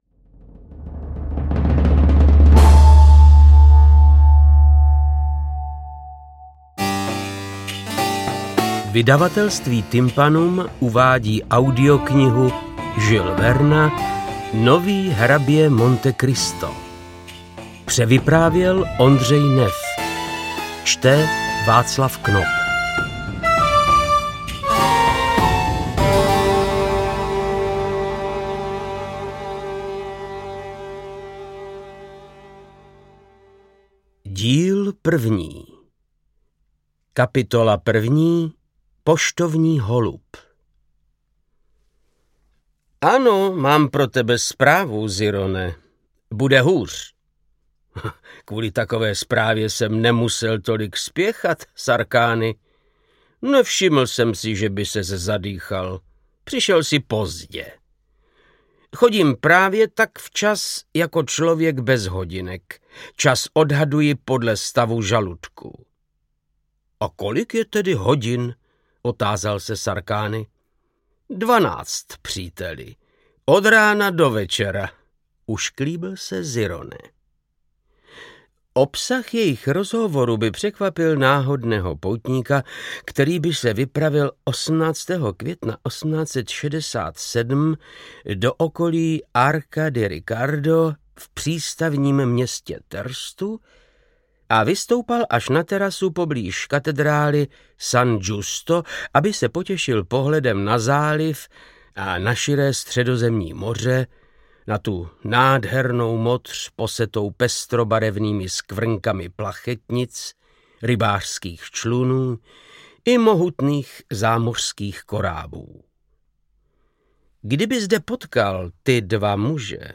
Interpret:  Václav Knop